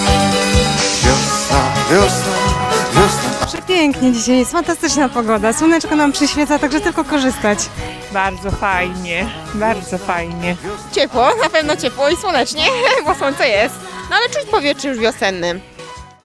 Pogoda dzisiaj dopisuje – dopisują również humory mieszkańców miasta, których zapytaliśmy czy wiosenny nastrój już zaczął im się udzielać.